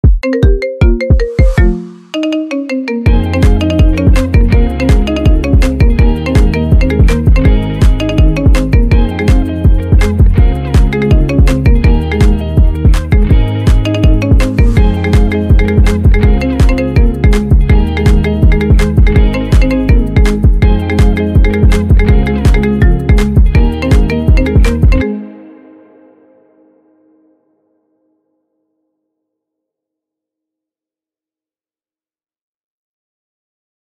Kategoria Marimba Remix